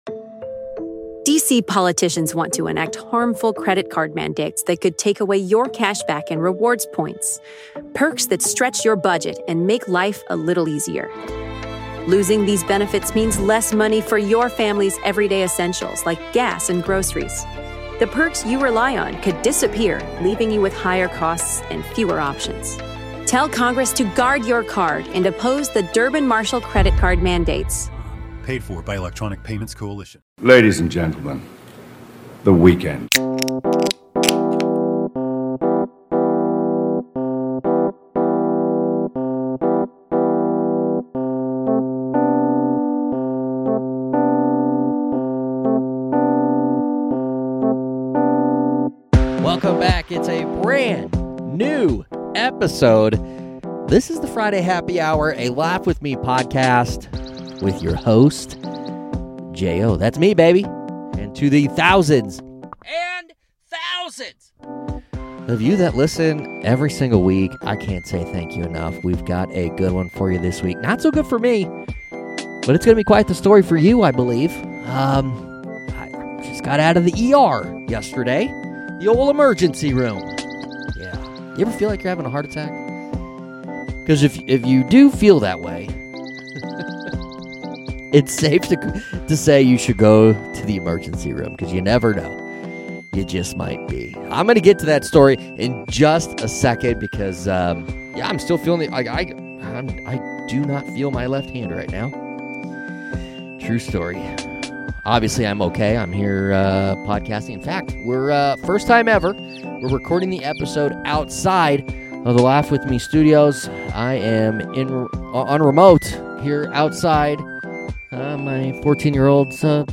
Comedy, Stand-up